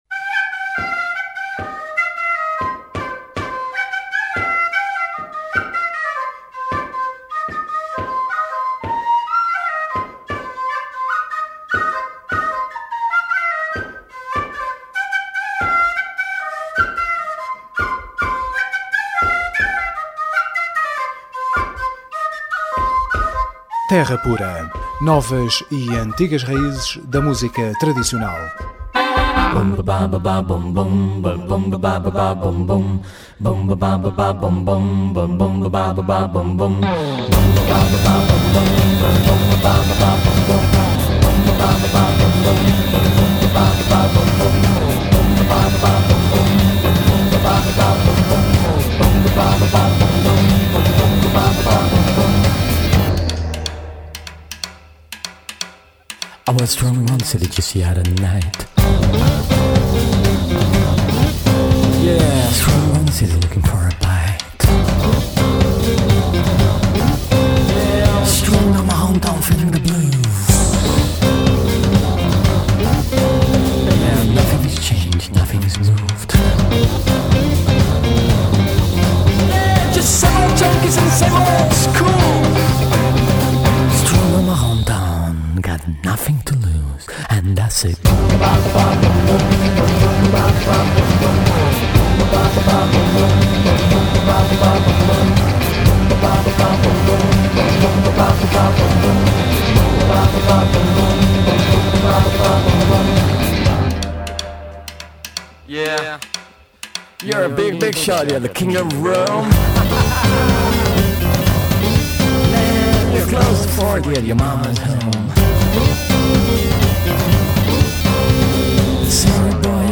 Emissão especial Terra de Abrigo com Paulo Furtado, mentor dos projectos Legendary Tigerman e Wraygunn que, a par de Tédio Boys, têm contribuído de forma decisiva para que Coimbra se tenha tornado, nesta última dúzia de anos, numa cidade encubadora de projectos de rock de língua inglesa com capacidade de se internacionalizarem.
Terra Pura 30ABR12: Entrevista Wraygunn